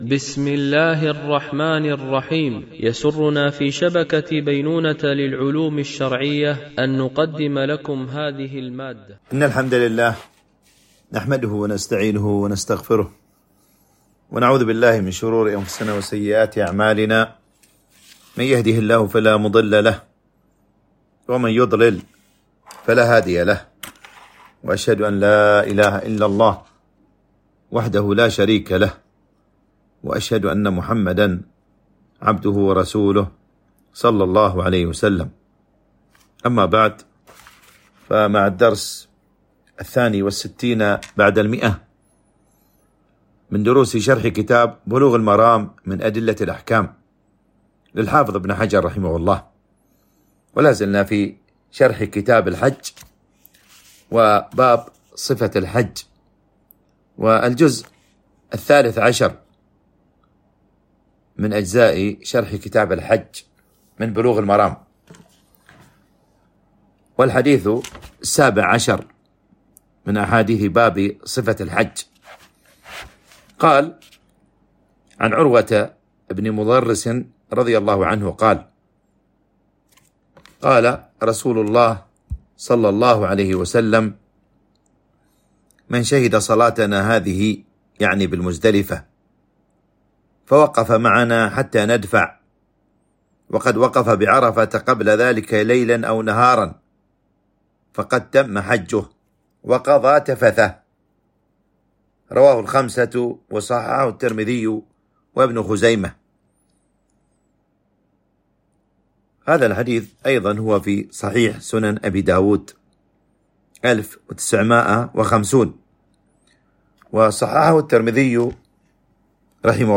التنسيق: MP3 Mono 44kHz 64Kbps (VBR)